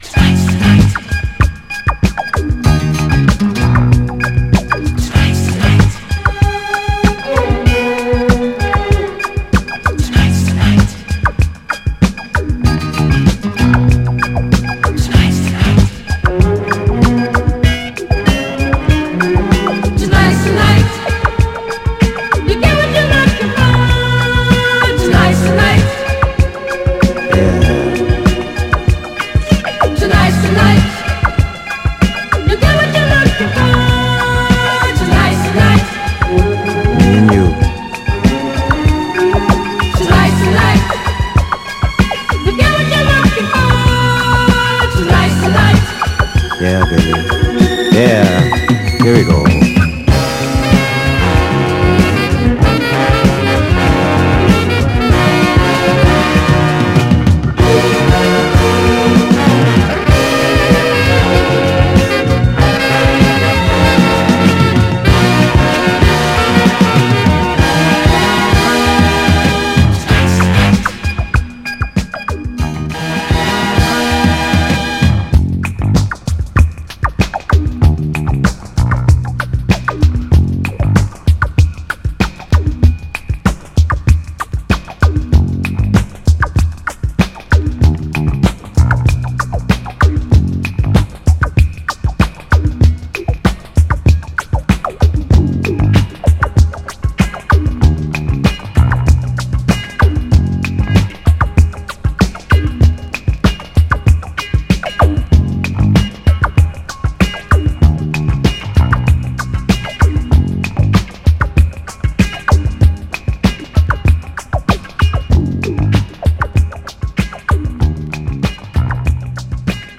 尖ったコズミック・グルーヴを纏った、幅広いDJ諸氏にオススメできるミッド・ブギー/ファンクです！
エレピとドープなブレイクビーツ展開がカッコいい、フリップのテイクもオススメです。
盤は細かい表面スレありますが、音への影響は少なくプレイ良好です。
※試聴音源は実際にお送りする商品から録音したものです※